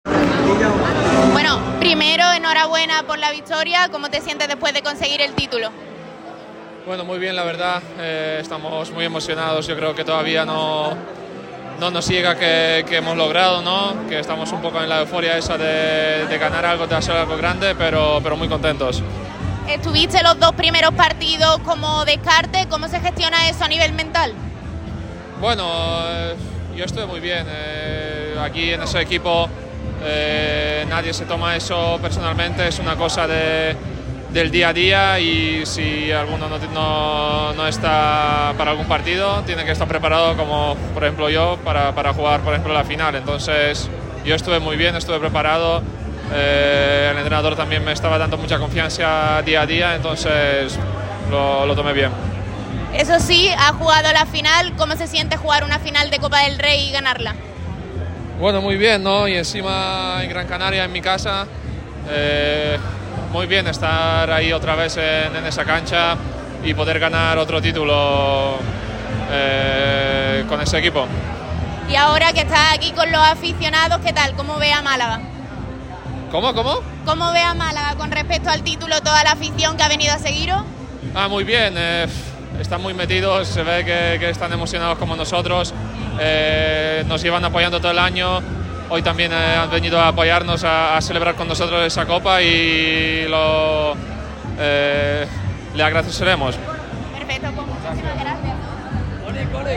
Durante la visita a Unicaja Banco, Radio MARCA Málaga ha tenido la oportunidad de charlar con Olek Balcerowski y Tyson Pérez.
OLEK-EN-LA-FIESTA-DE-LA-COPA.mp3